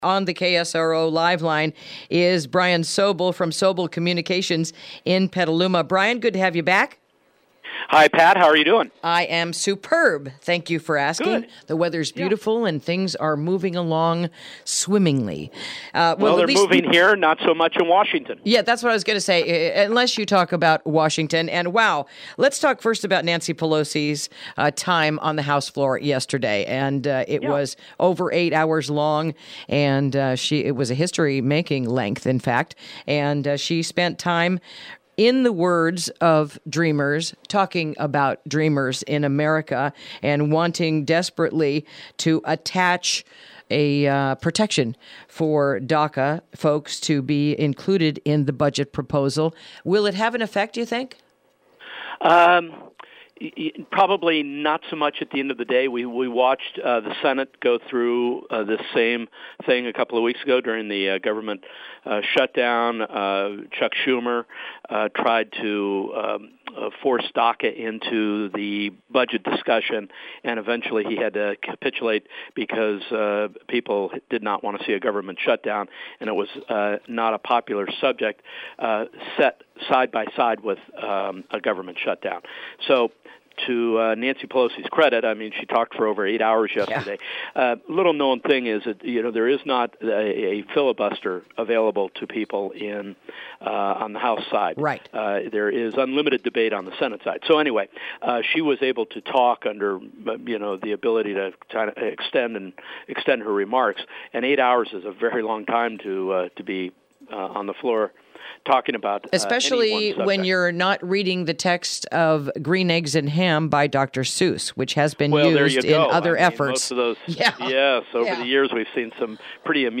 Interview: DACA and the Possible Government Shutdown